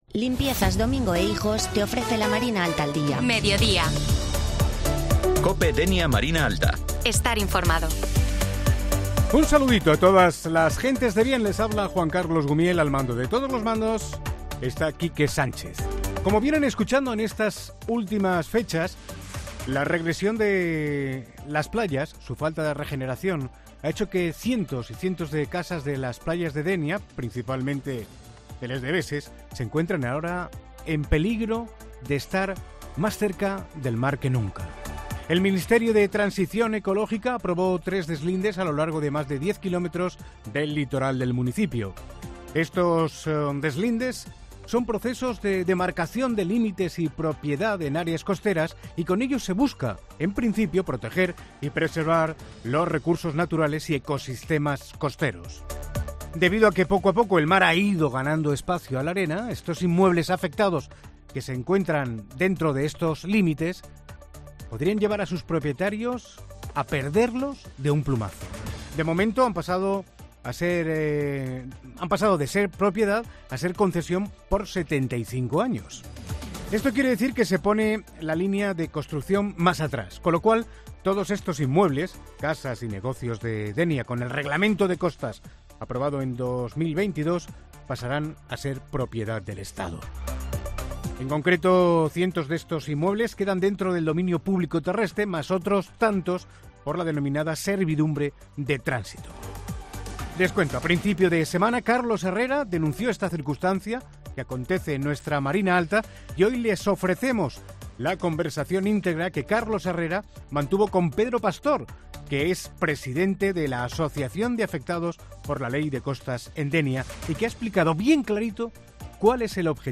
AUDIO: Escucha a Carlos Herrera en la entrevista